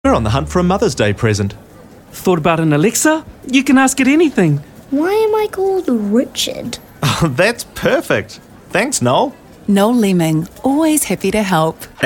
Voice Samples: Mother's Day
EN NZ
male